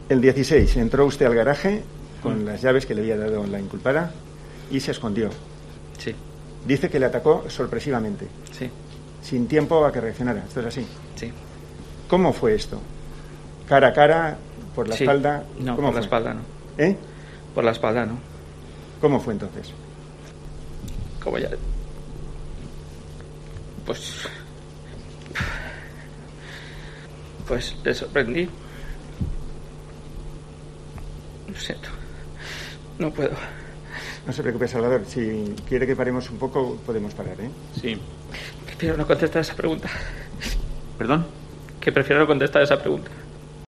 se rompe y no puede explicar los detalles del crimen